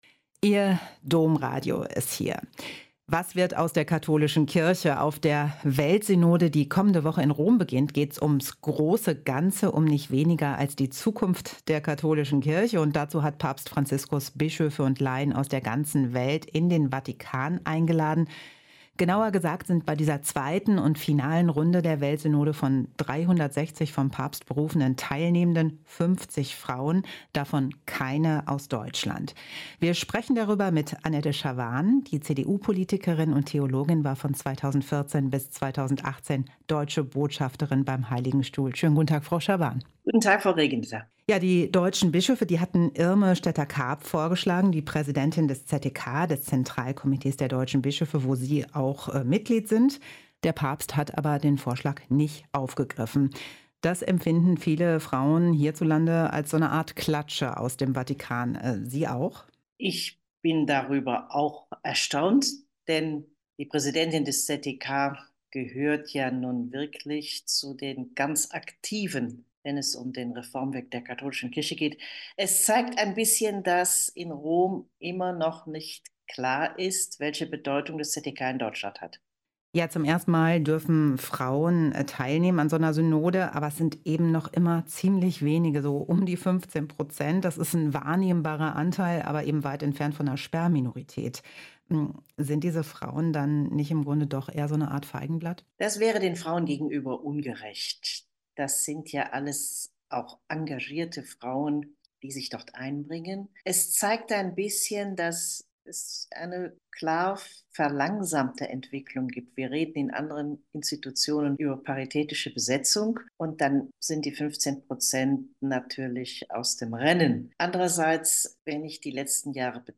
Ein Interview mit Annette Schavan (Frühere Bundesministerin und Vatikan-Botschafterin)